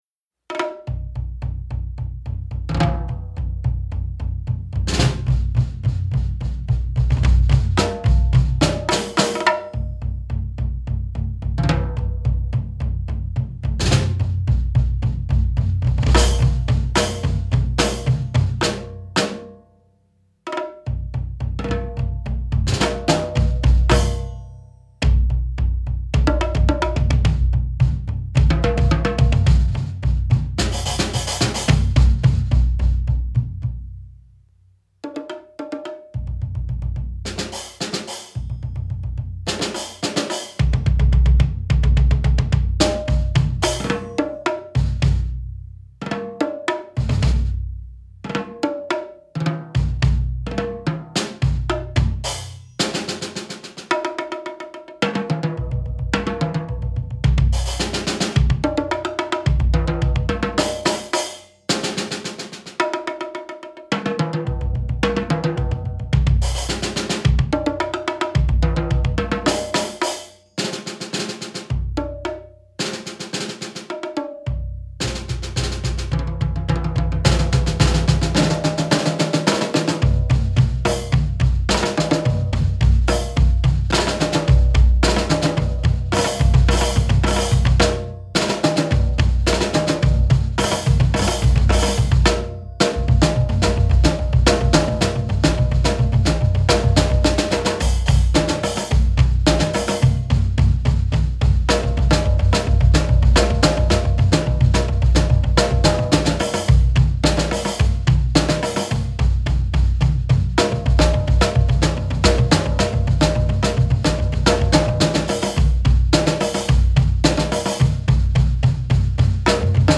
A visual wonderment for battery trio.